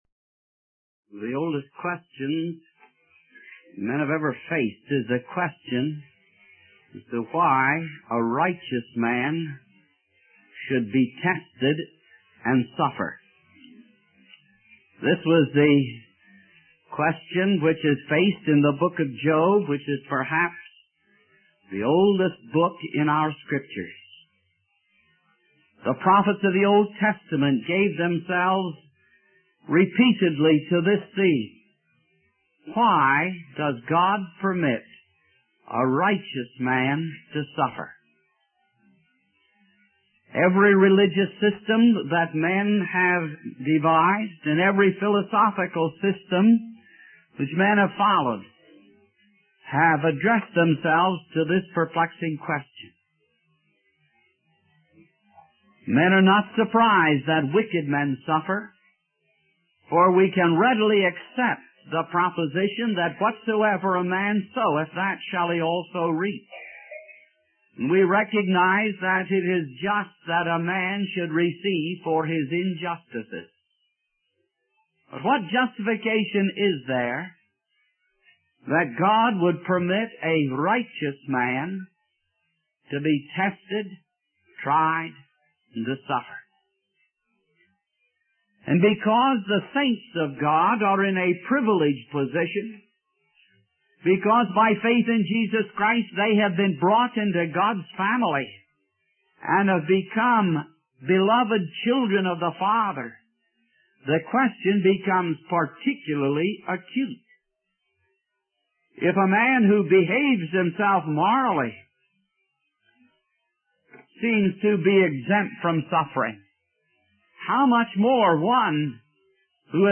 In this sermon, the speaker emphasizes the importance of sobriety in our understanding of trials and testing. He explains that sobriety does not refer to the use of intoxicants, but rather to seeing things in their true light. The speaker highlights the need to recognize that testing comes from God, not Satan, and that it is meant to mold and conform us to the image of Christ.